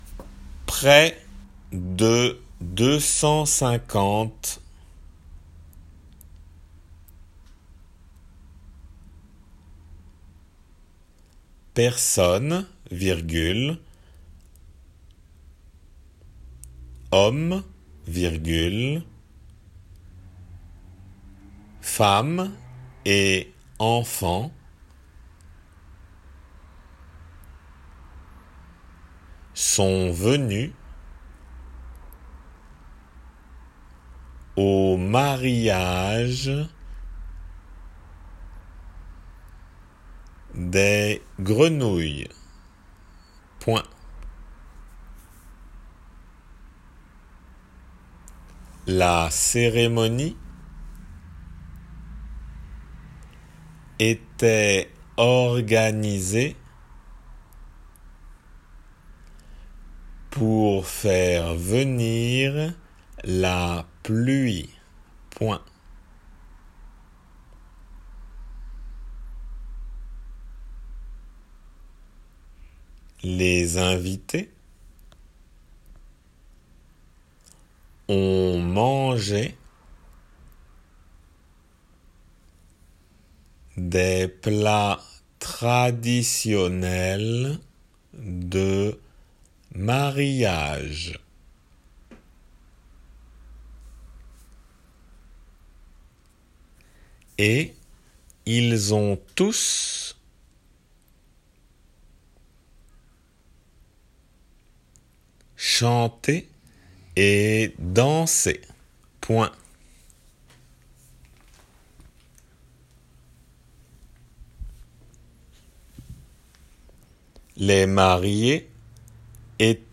仏検受験用　2級デイクテ12－音声
デイクテの速さで